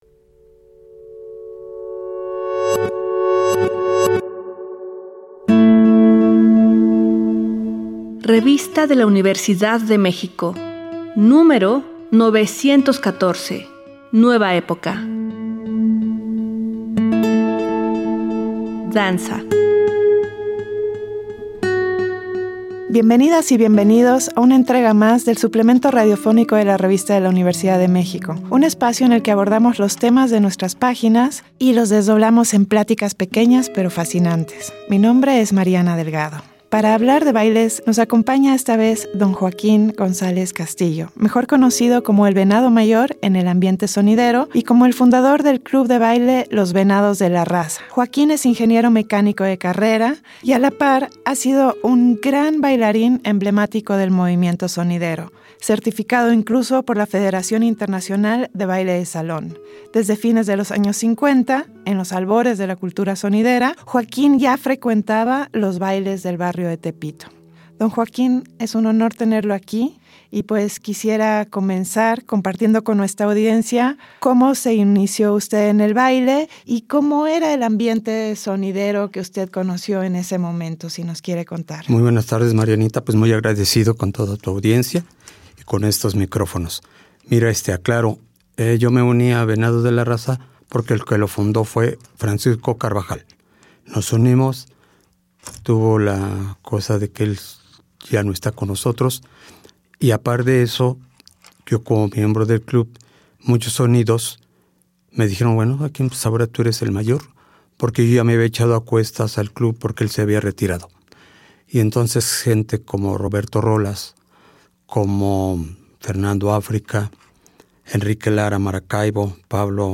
Fue transmitido el jueves 15 de noviembre de 2024 por el 96.1 FM.